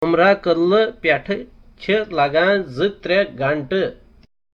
A conversation on visiting the mosque at Hazratbal, ten miles from downtown Srinagar.